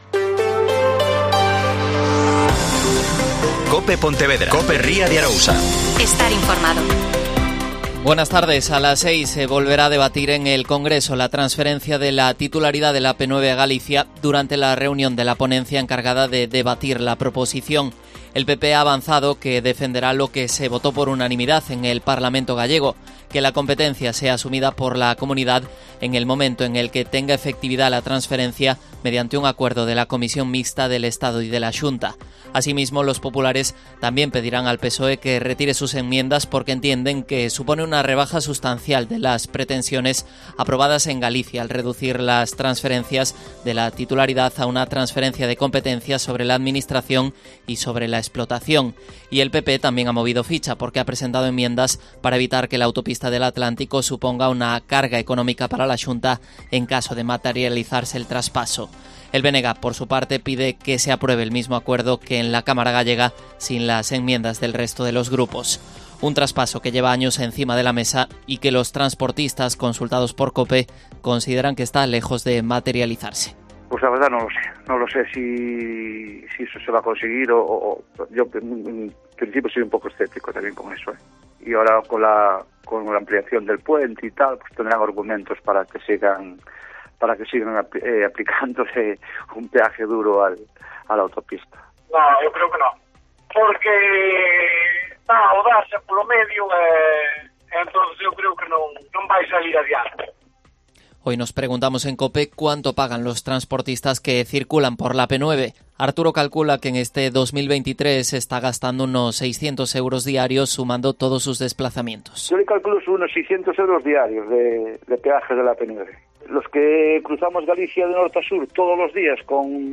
Mediodía Pontevedra COPE Pontevedra y COPE Ría de Arosa (Informativo 14:20h)